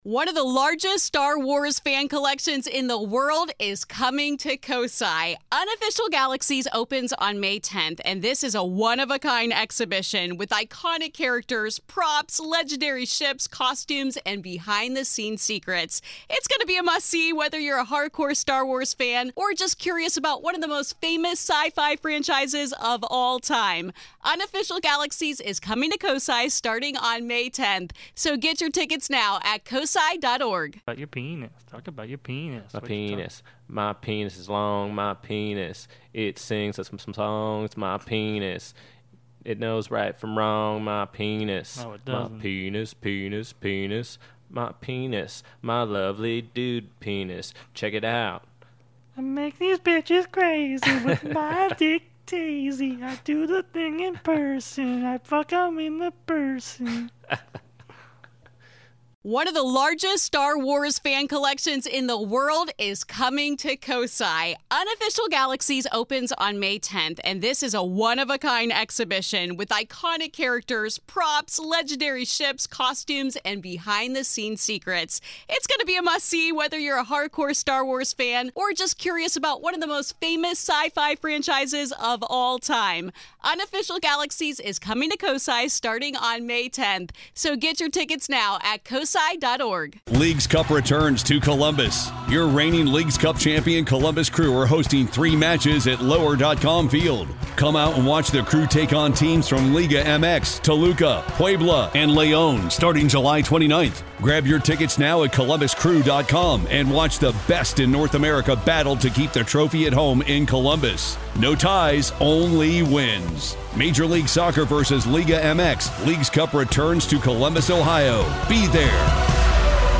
A very short mic test while we were pretty drunk. Welcome to the Second Shot Podcast!